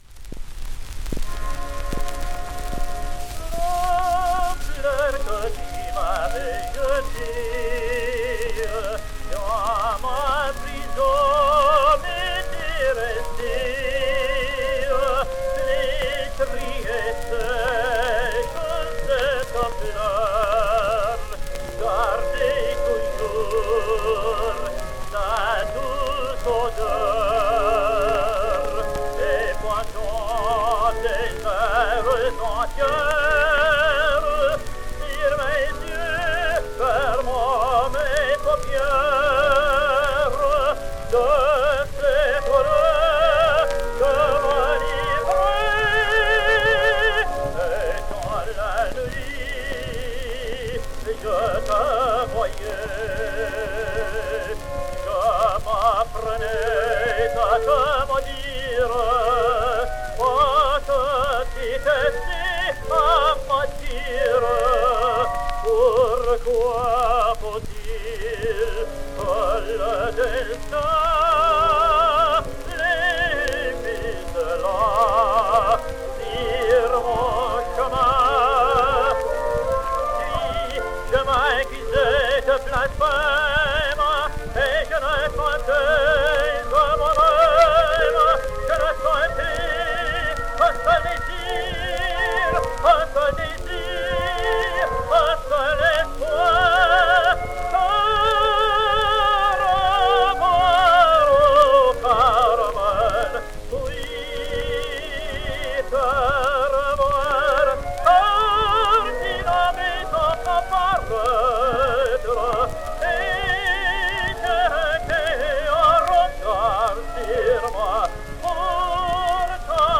And he was something of an impostor, posing alternately as an Italian and a French tenor, as having toured with Fritz Kreisler, as having sung at Covent Garden, which is all untrue; further, as having studied with either Victor Maurel or Giovanni Sbriglia (the teacher of Pol Plançon, Jean and Édouard de Reszke), which is highly improbable; and as having toured also with Nellie Melba, which was highly imprecise (he only supported her in one concert in San Diego in February 1916).